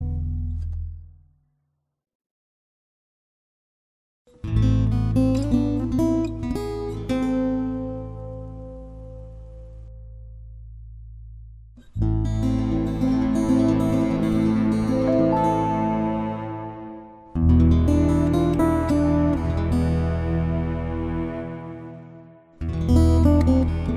Applause Section Cut Duets 2:23 Buy £1.50